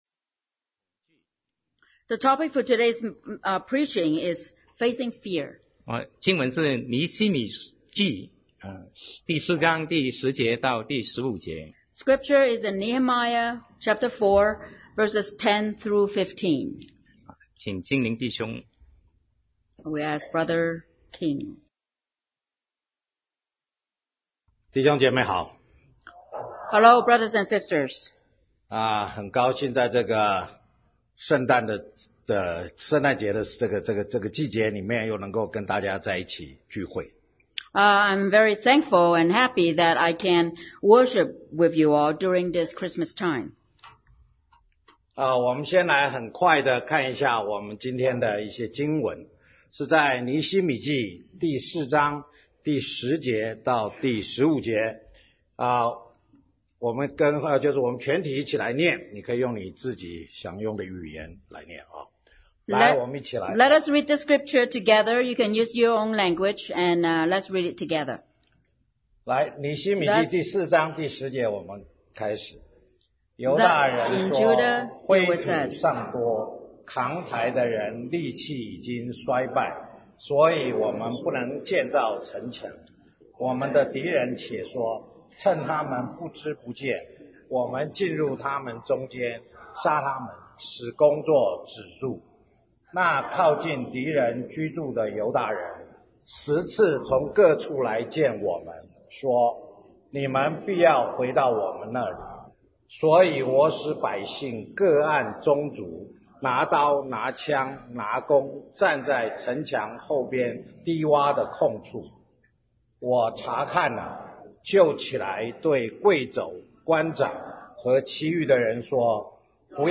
Sermon 2017-12-03 Facing Fear